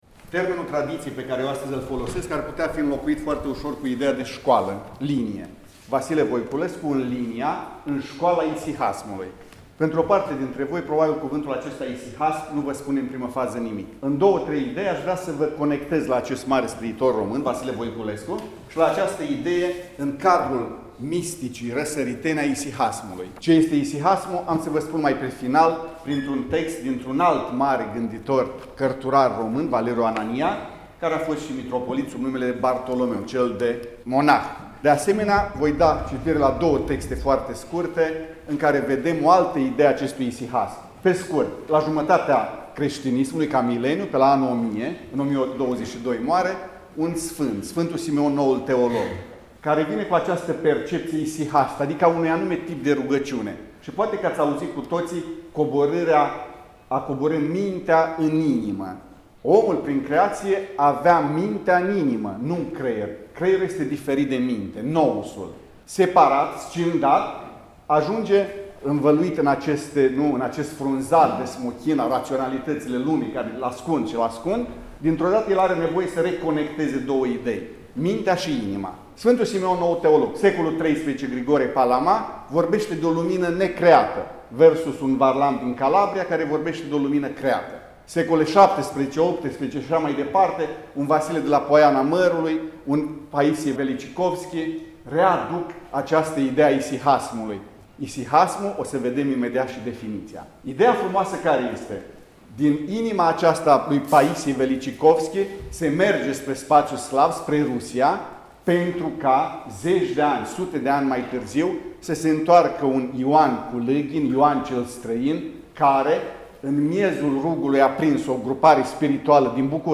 Sesiunea a avut loc în Sala „Ștefan Procopiu” din incinta Muzeului Științei și Tehnicii din cadrul Complexului Muzeal Național „Moldova” Iași.